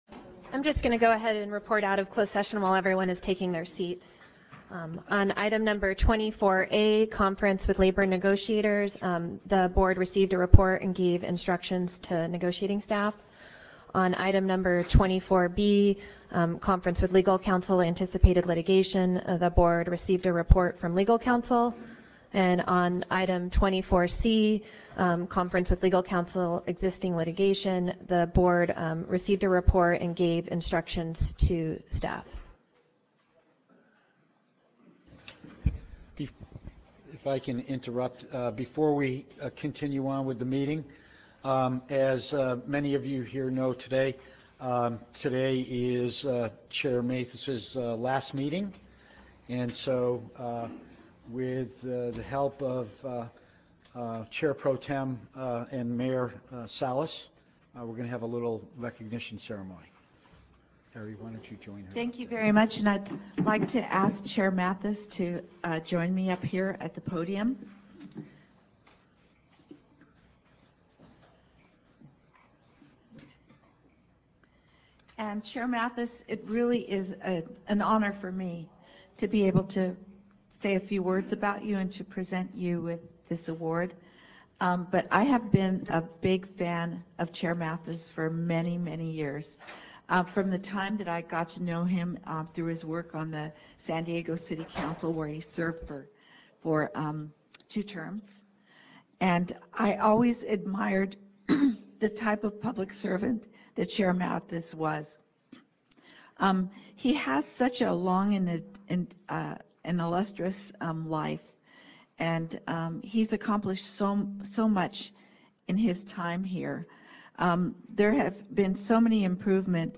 Meeting Type Board Meeting